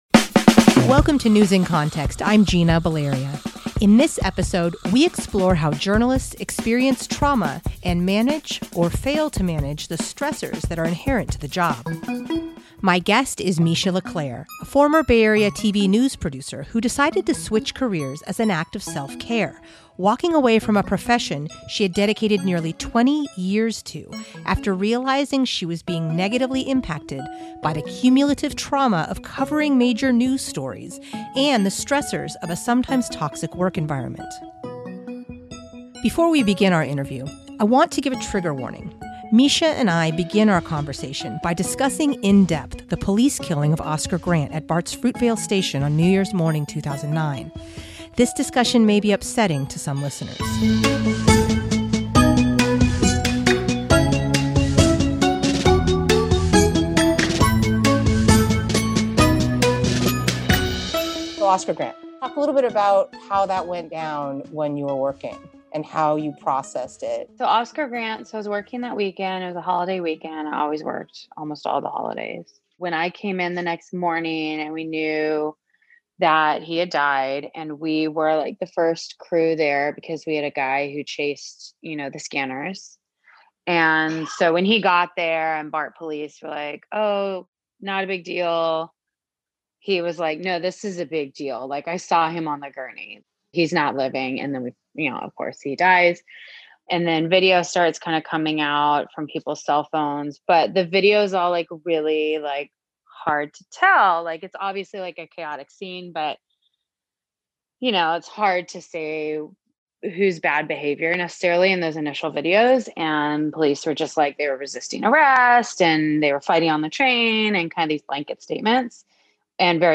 This discussion may be upsetting for some listeners.